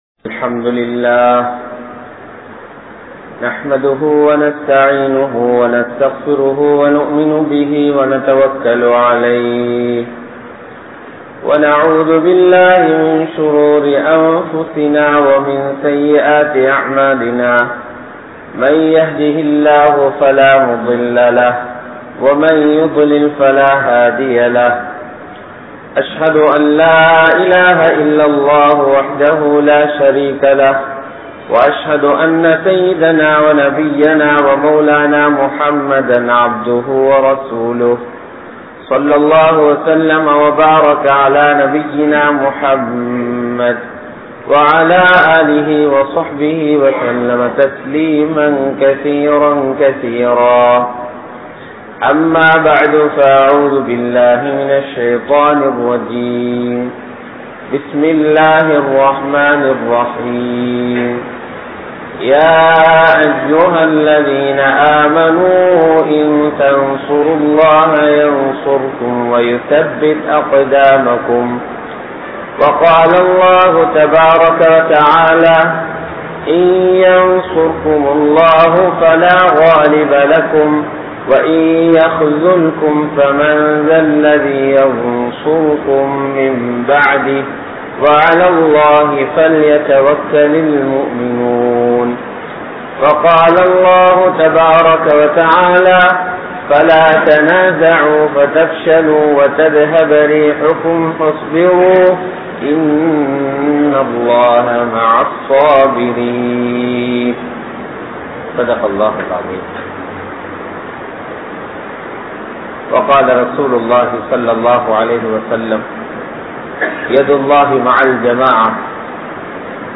Soathanaihalil Vettri(Victory)(சோதனைகளில் வெற்றி) | Audio Bayans | All Ceylon Muslim Youth Community | Addalaichenai
Dehiwela, Junction Jumua Masjith